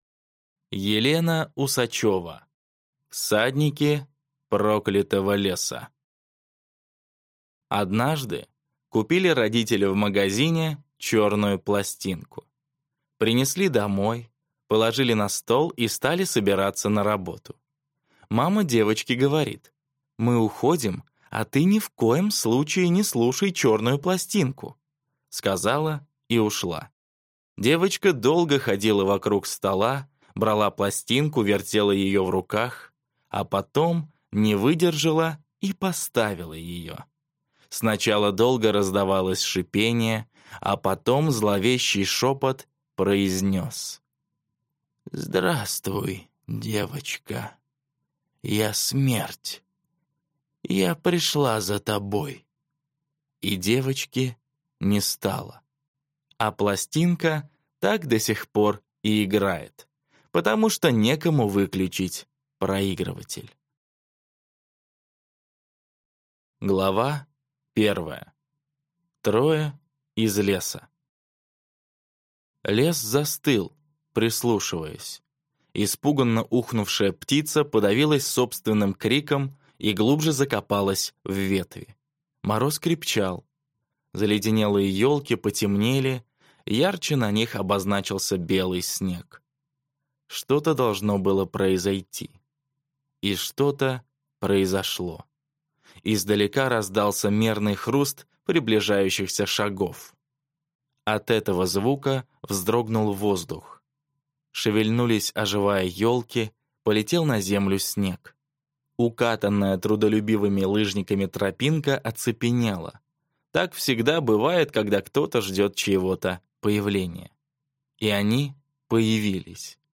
Аудиокнига Всадники проклятого леса | Библиотека аудиокниг
Прослушать и бесплатно скачать фрагмент аудиокниги